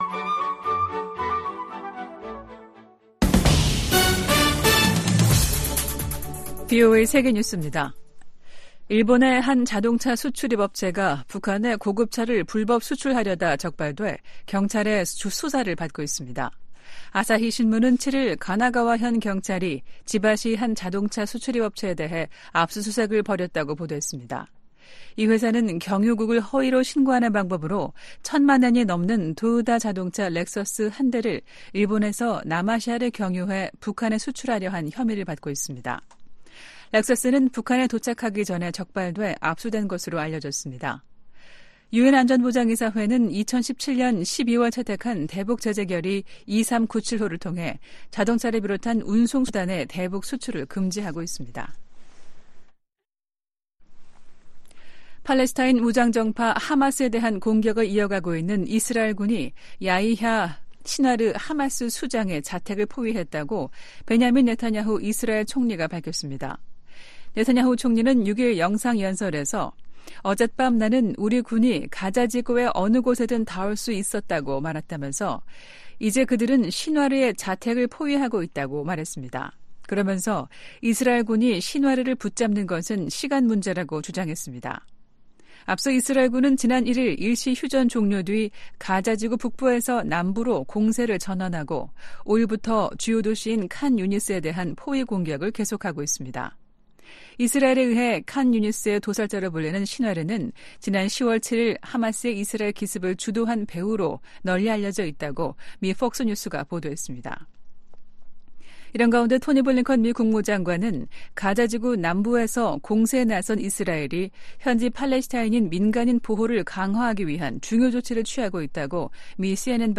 VOA 한국어 아침 뉴스 프로그램 '워싱턴 뉴스 광장' 2023년 12월 8일 방송입니다. 한국 정부가 처음으로 김정은 북한 국무위원장의 딸 김주애 후계자 내정설에 관해 가능성 높다는 공식 판단을 내놨습니다. 미한일 안보 수장들이 오는 9일 서울에서 역내 안보 현안들을 집중 논의합니다. 자유를 향한 탈북민 가족들의 이야기를 그린 다큐 영화가 새해 초 공영방송을 통해 미국의 안방에 방영됩니다.